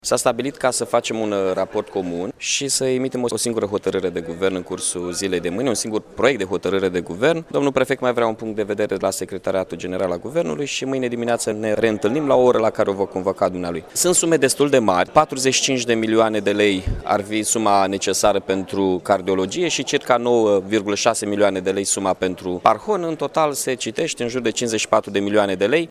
La şedinţa de astăzi a Comitetului pentru Situaţii de Urgenţă, primarul de Iaşi, Mihai Chirica, a spus că varianta unei solicitări comune privind alocarea de fonduri pentru cele două unităţi medicale este una bună: